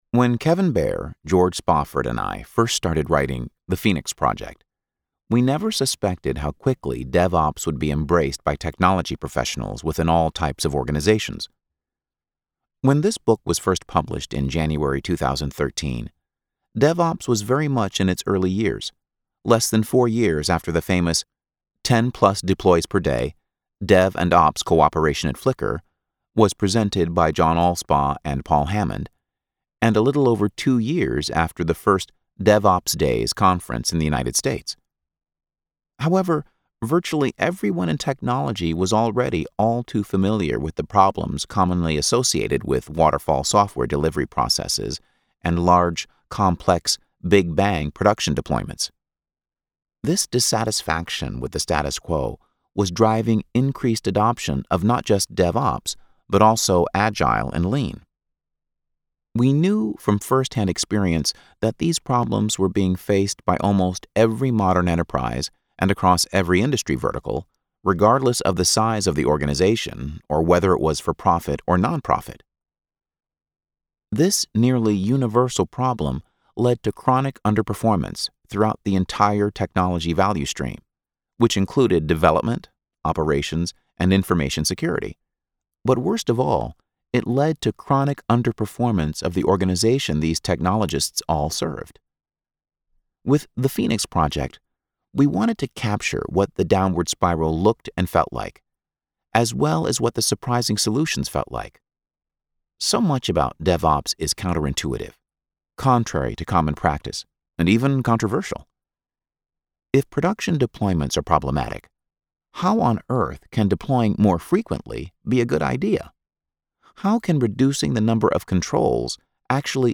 The Phoenix Project - Audiobook Excerpt